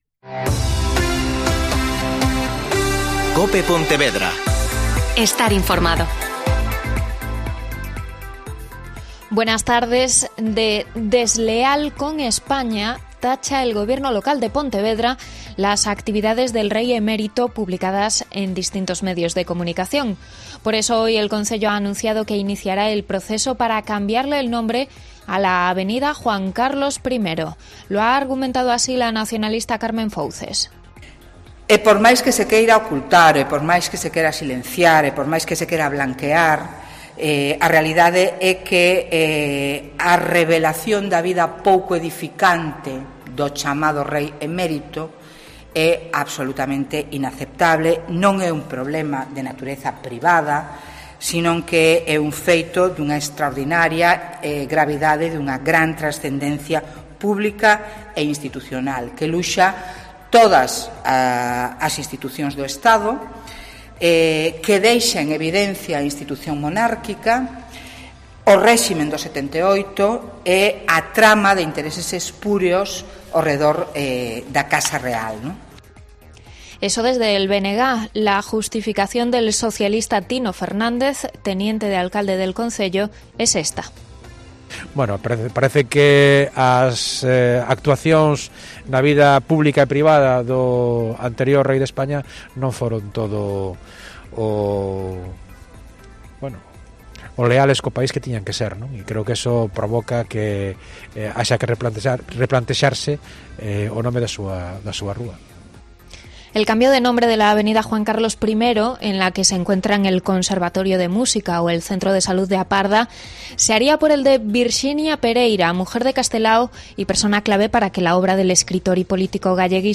Mediodia COPE Pontevedra (Informativo 14,20h)